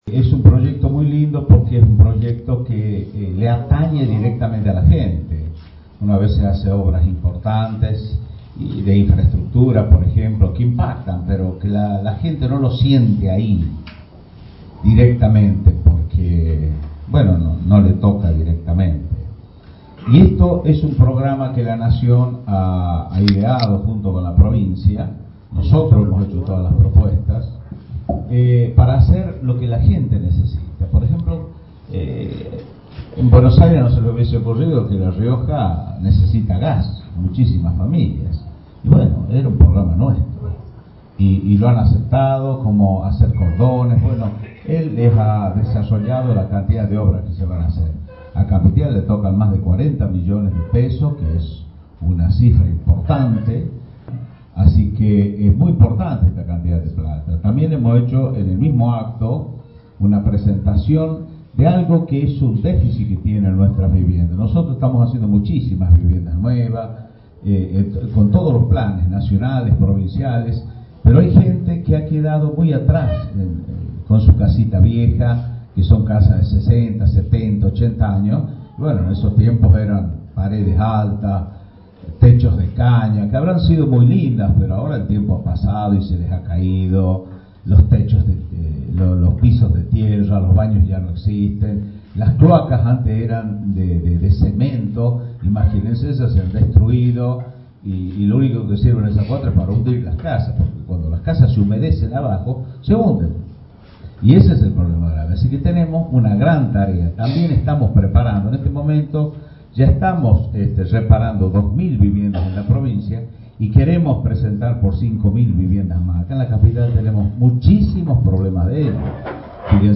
Luis Beder Herrera, gobernador
El mandatario realizó estas afirmaciones al participar este martes en la presentación de obras que forman parte del plan nacional “Más Cerca” para los departamentos Famatina y Capital.